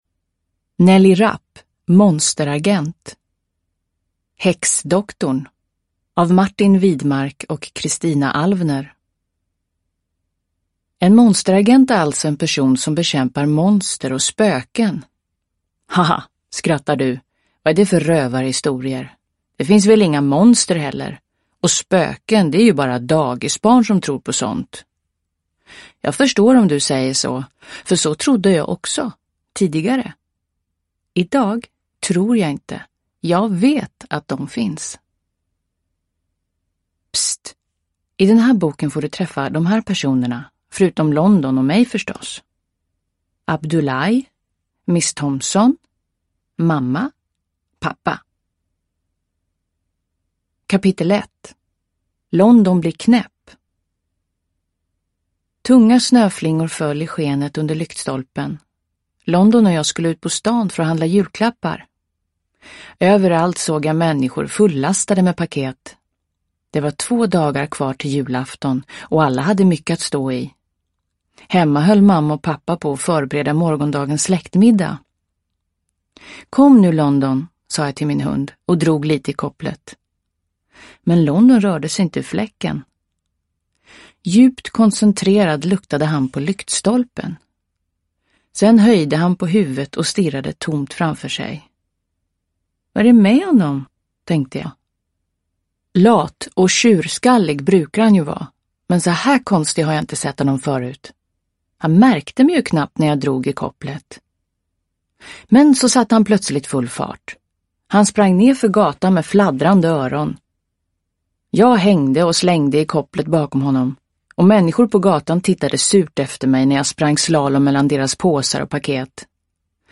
Häxdoktorn - och den sista zombien / Ljudbok